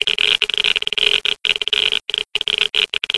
geiger_level_2.ogg